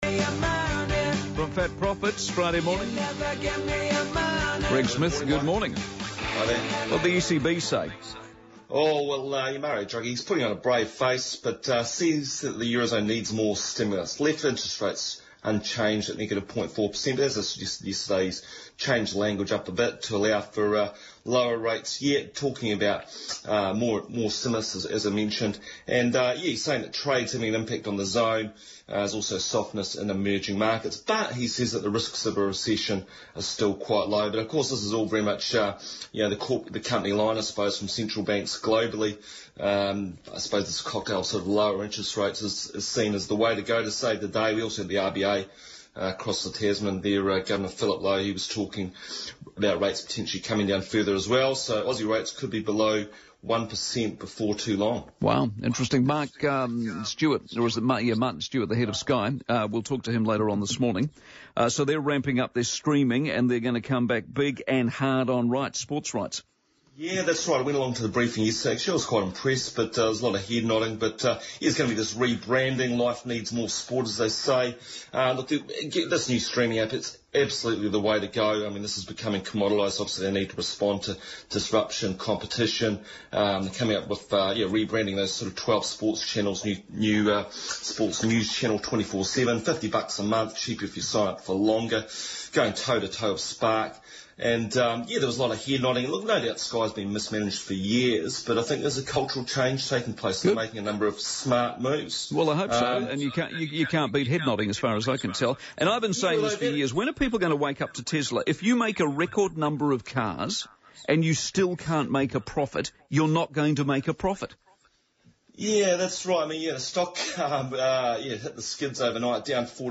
Newstalk Radio Interview